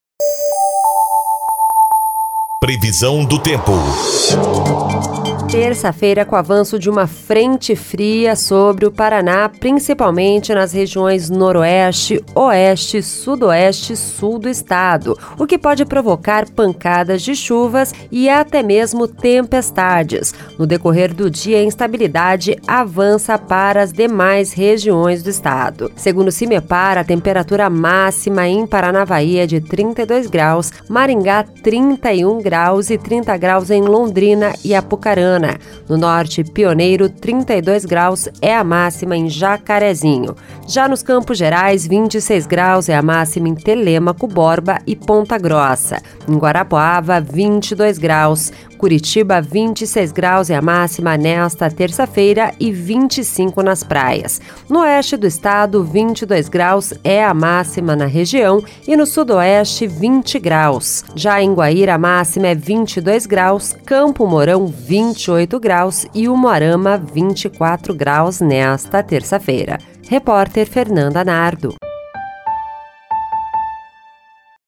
Previsão do Tempo (03/05)